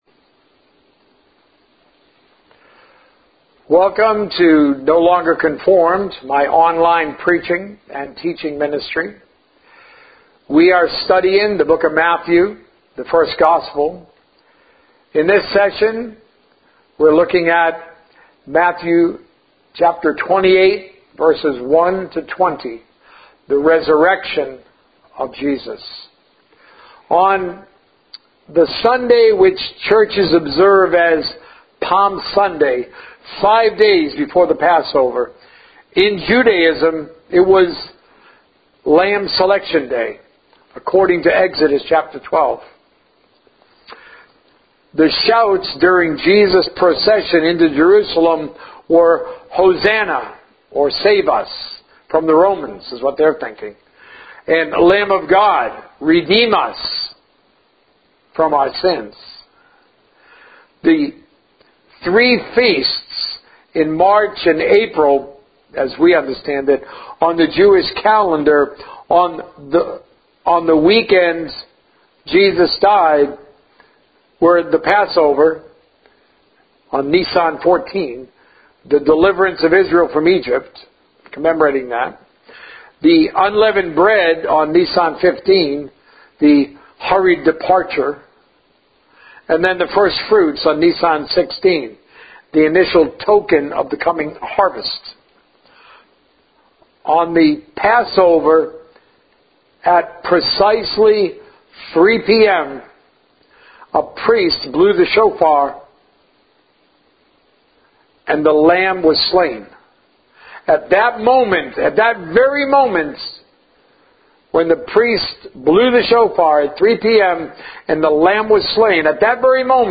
A message from the series "The First Gospel." Prayer that Turns Jesus' Head